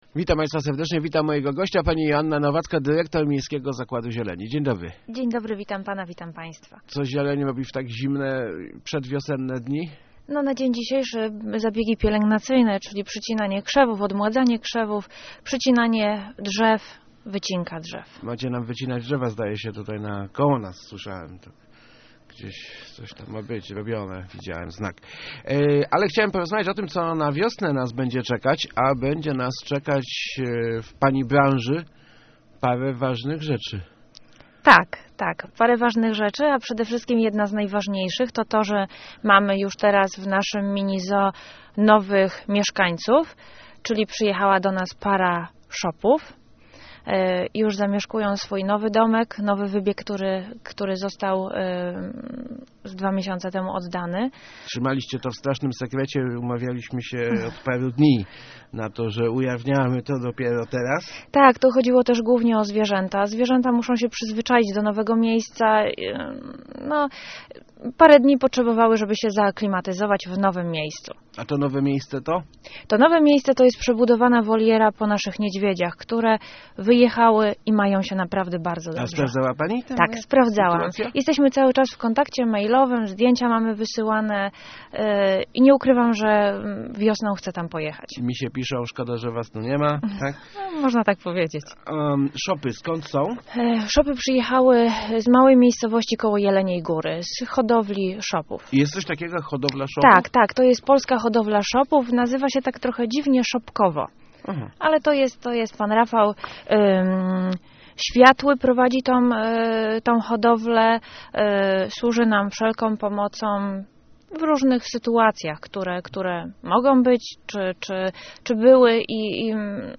To chyba prawda, dzisiaj słuchałem Pani w Elce- ma Pani milutki głos.